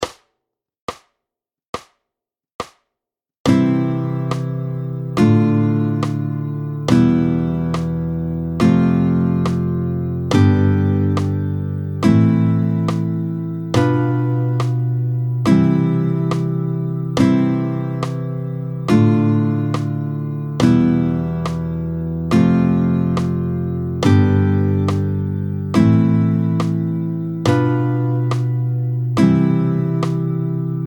13-01 La gamme de Do majeur, tempo 70